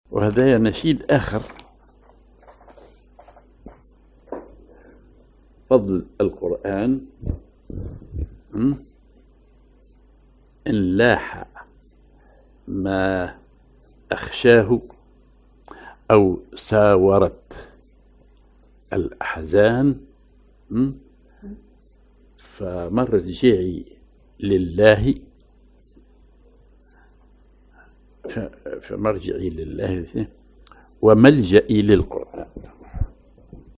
genre نشيد